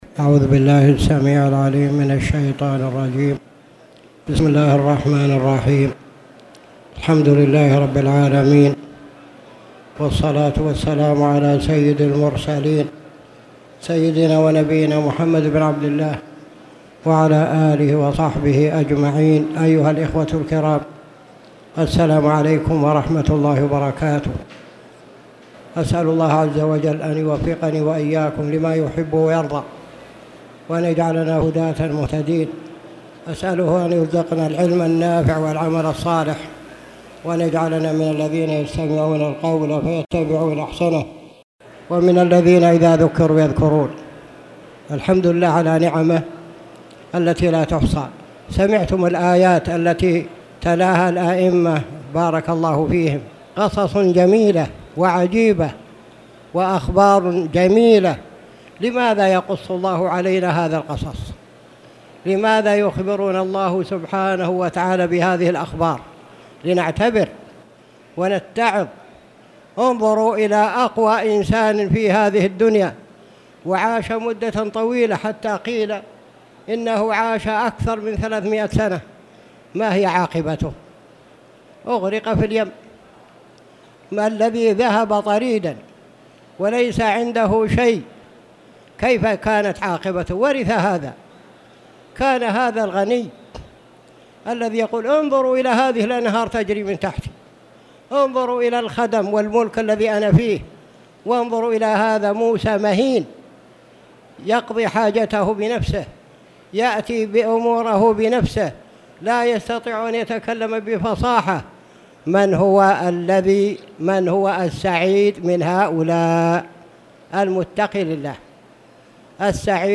تاريخ النشر ١٤ رمضان ١٤٣٧ هـ المكان: المسجد الحرام الشيخ
14rmdan-tfsyr-swrh-albqrh220.mp3